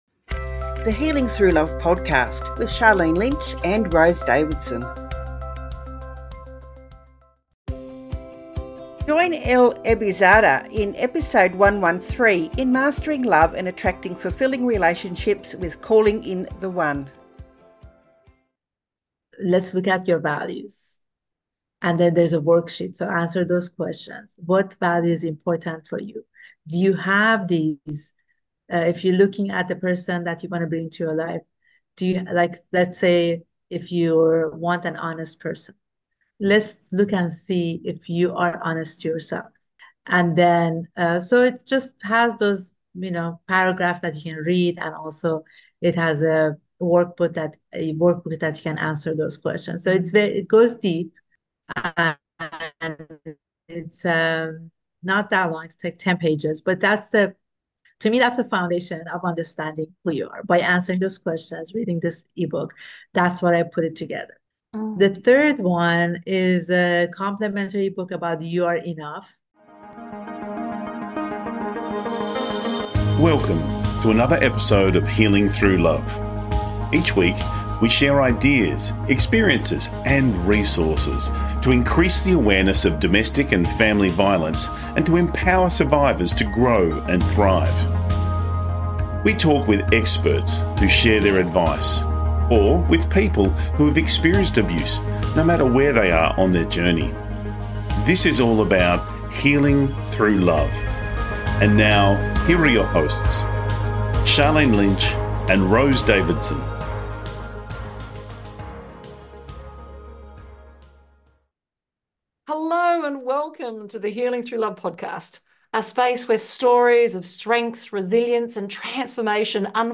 Key Points from the Interview: How self-love and emotional healing are essential steps to attract lasting, fulfilling relationships. Strategies for breaking free from unhealthy relationship cycles and embracing new possibilities. The importance of authentic communication and clear intentions in manifesting healthy partnerships.